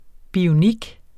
Udtale [ bioˈnig ]